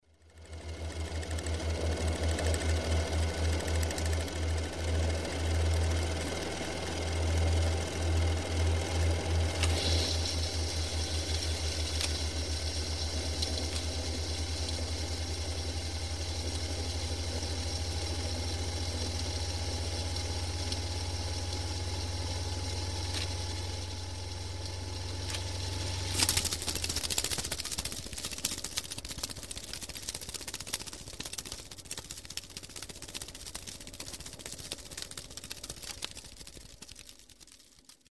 Звуки аудиокассеты
Окунитесь в атмосферу прошлого с подборкой звуков аудиокассет: характерное шипение пленки, щелчки перемотки, фоновый шум магнитофона.